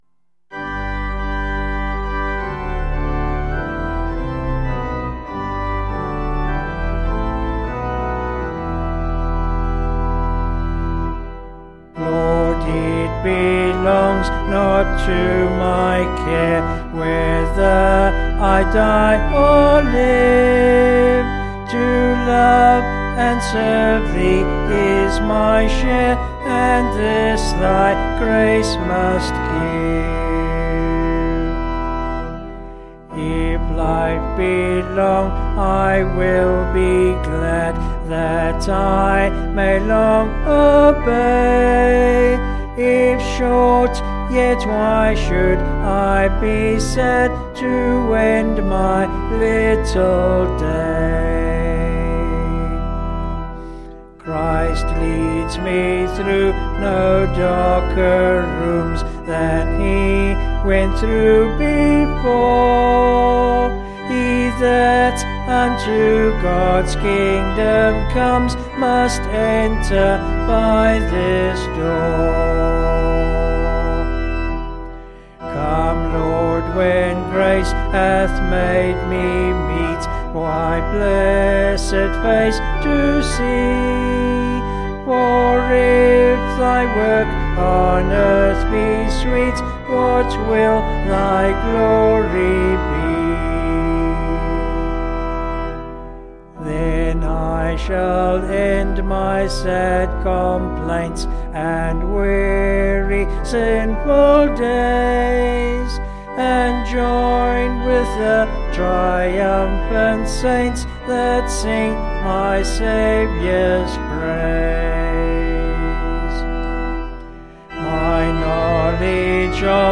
Vocals and Organ   264.2kb Sung Lyrics